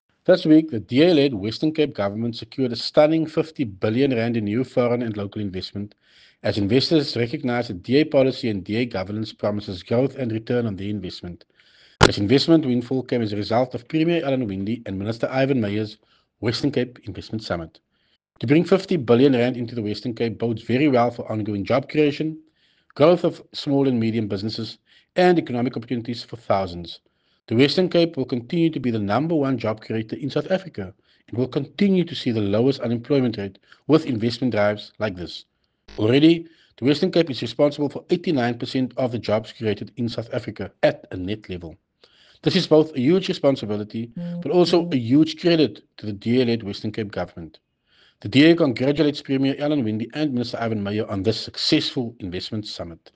soundbite by Tertuis Simmers
Tertuis-Simmers-on-WCG-Investment.mp3